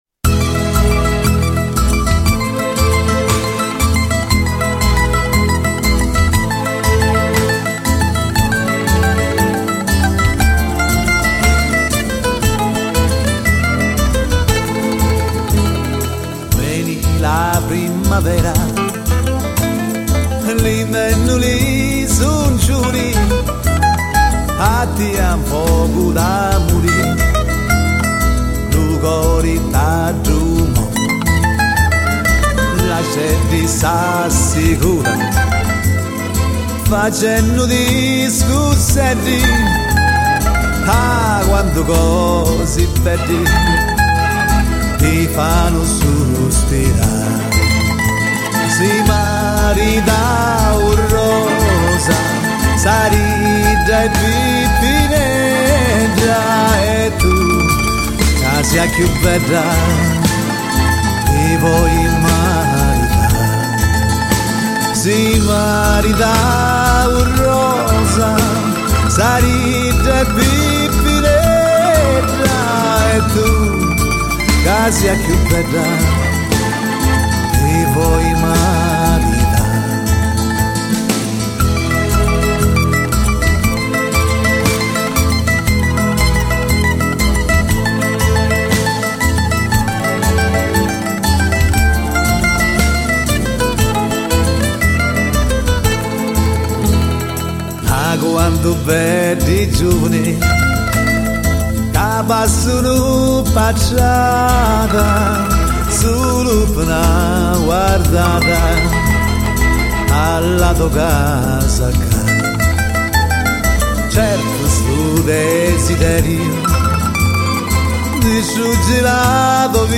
гитара
мандолина.
Я нашла тоже качество не очень, но песня до конца.